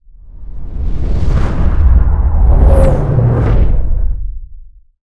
cinematic_deep_bass_pass_whoosh_04.wav